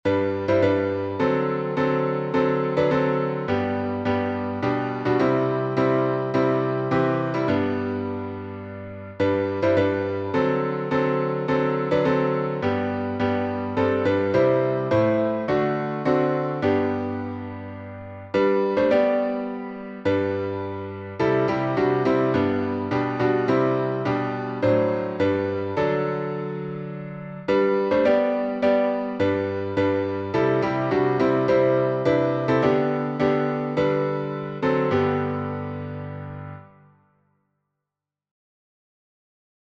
Draw Me Nearer — G major.